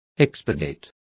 Complete with pronunciation of the translation of expurgating.